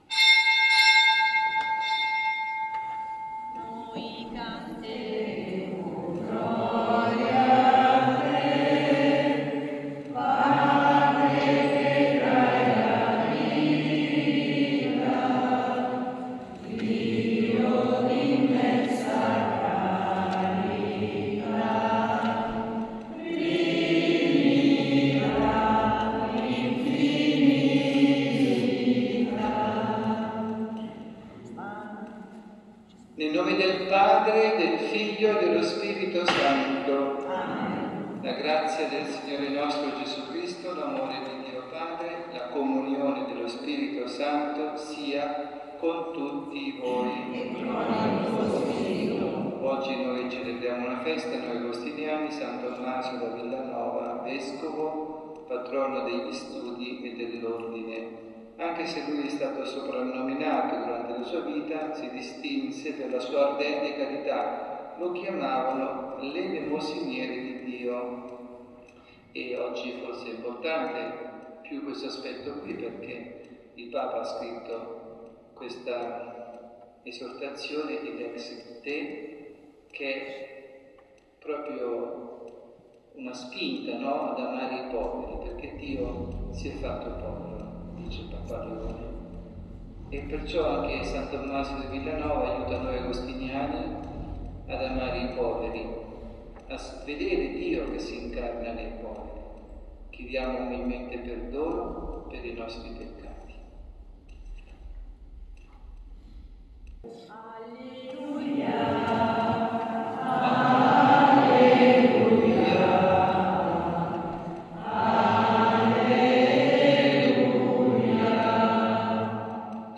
dalla Basilica di San Nicola